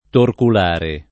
torculare [ torkul # re ]